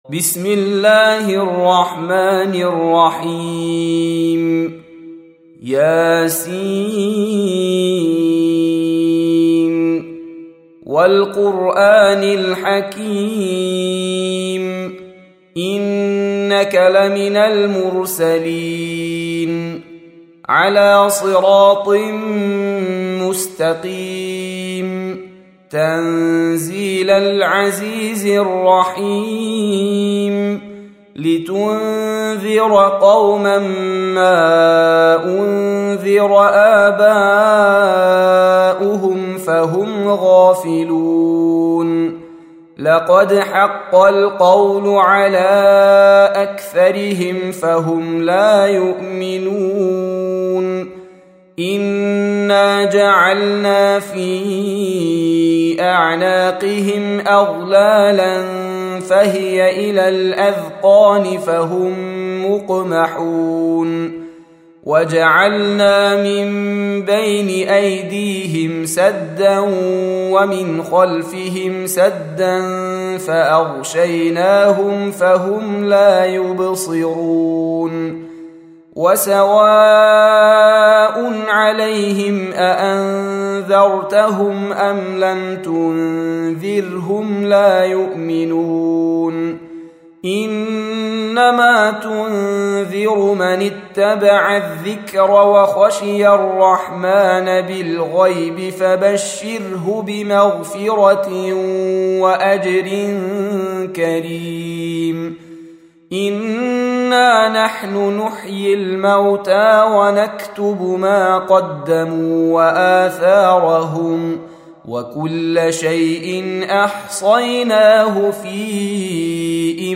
Audio Quran Tarteel Recitation
Surah Repeating تكرار السورة Download Surah حمّل السورة Reciting Murattalah Audio for 36. Surah Y�S�n. سورة يس N.B *Surah Includes Al-Basmalah Reciters Sequents تتابع التلاوات Reciters Repeats تكرار التلاوات